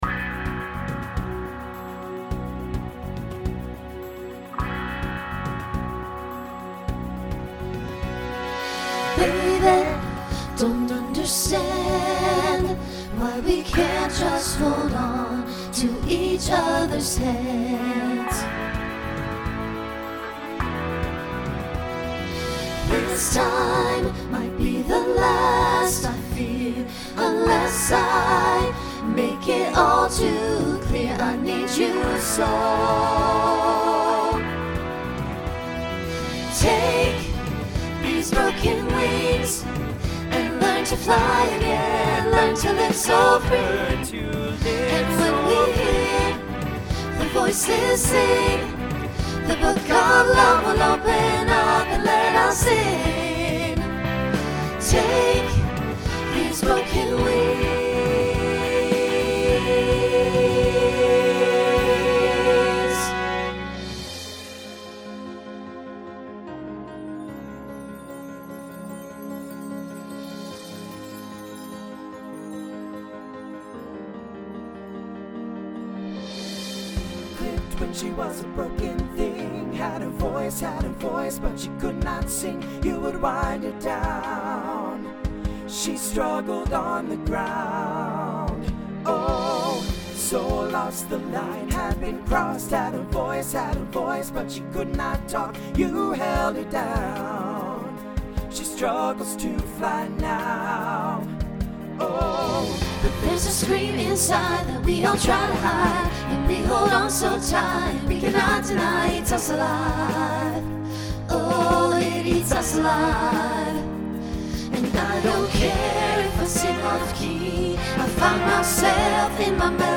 Genre Pop/Dance
Mid-tempo Voicing SATB